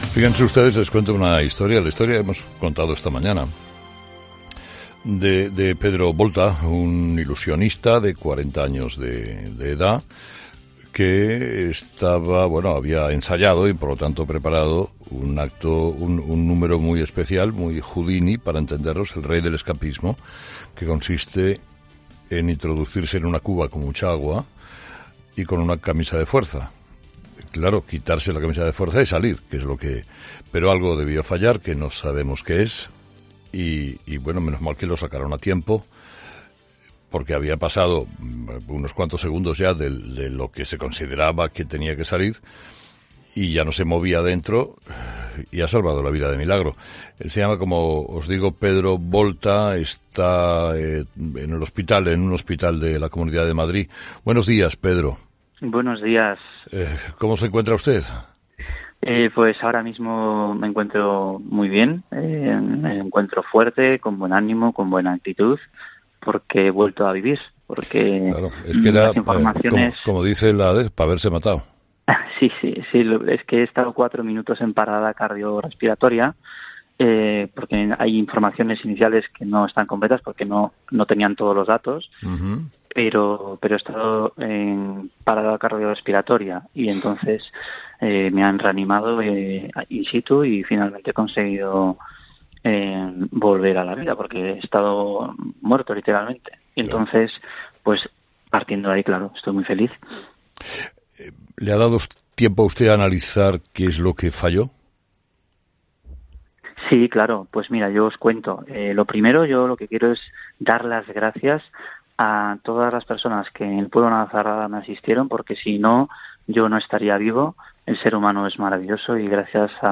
Entrevistado: